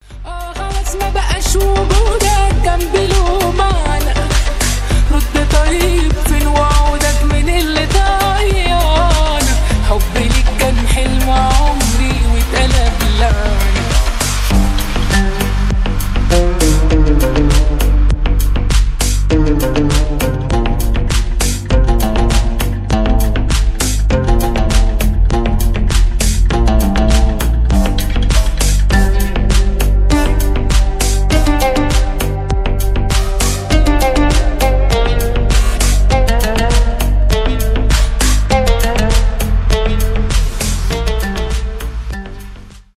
deep house , ремиксы , клубные , арабские